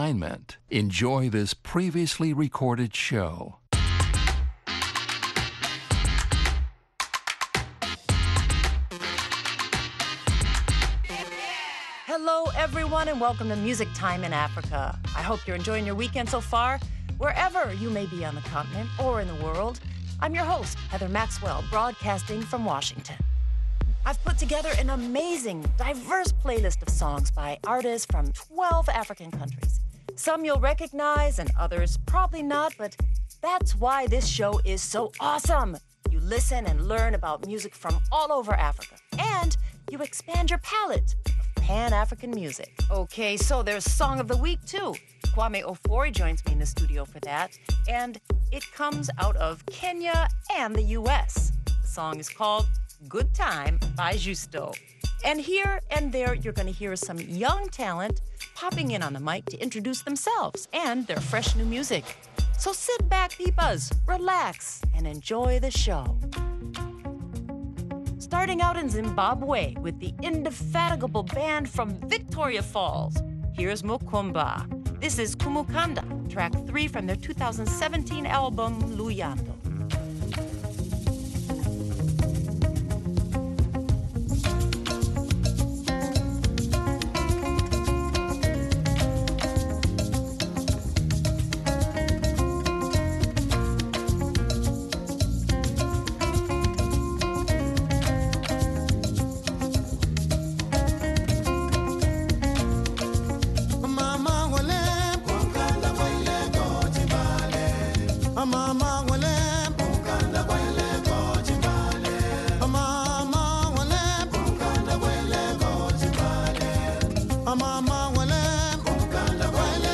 a humorous and catchy Afropop song